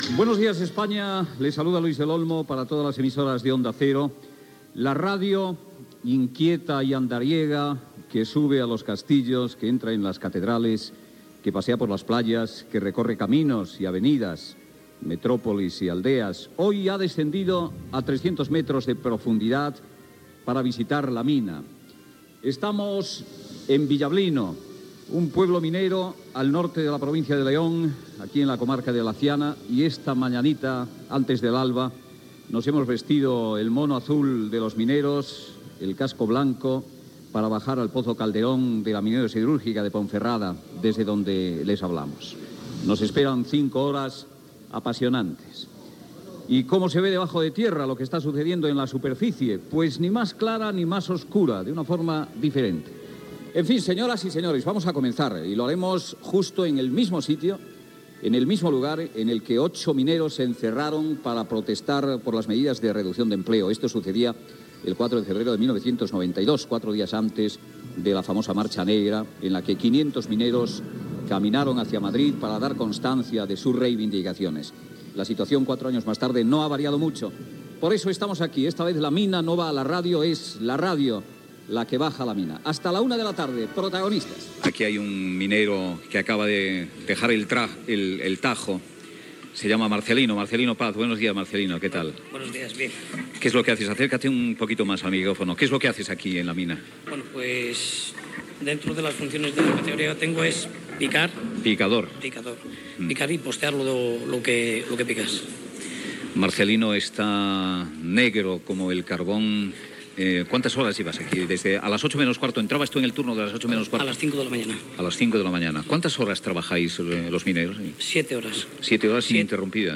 Inici del programa des del pozo Calderón de la mina de Villablino (Lleó) i comentaris amb els miners
Info-entreteniment